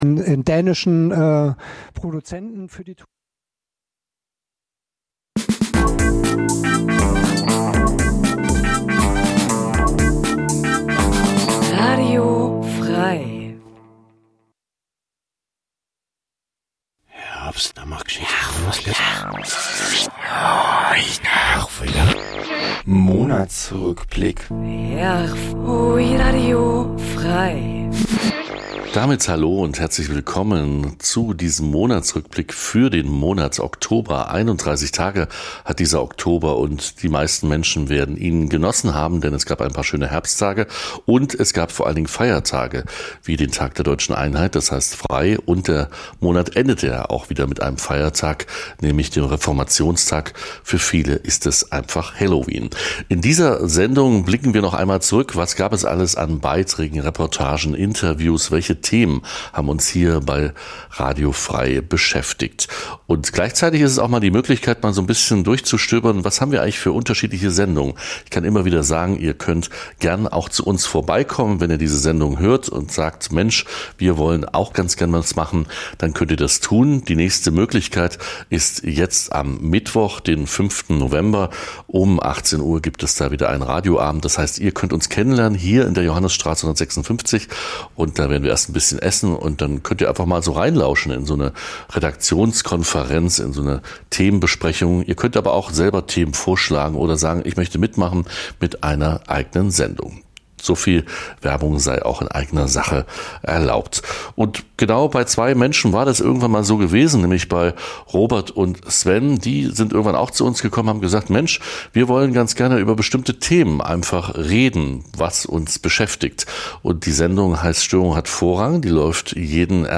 Werfen wir noch einmal einen "Blick" zur�ck in den vergangenen Monat, und genie�en Herausragendes, Banales und auch Peinliches (?) aus dem Programm von Radio F.R.E.I. Zusammengefasst und moderiert von wechselnden RedakteurInnen.